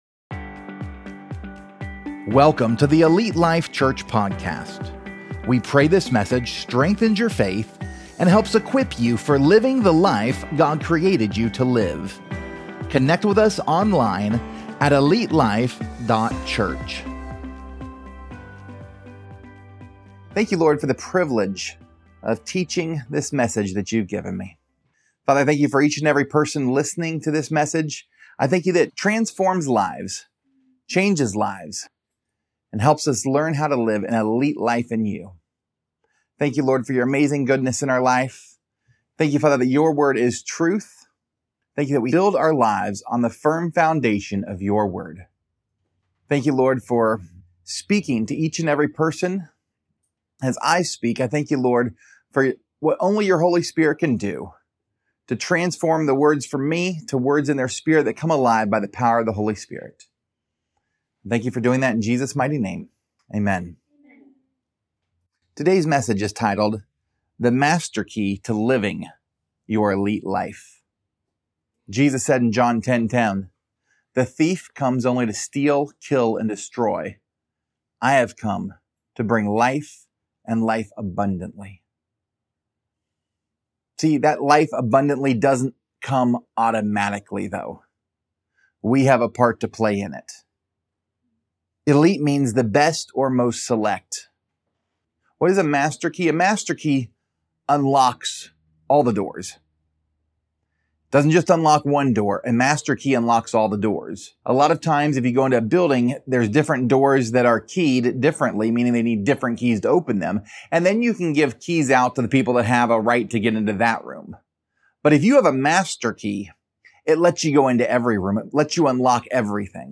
Prelude: The Master Key to Living Your Elite Life | Sermon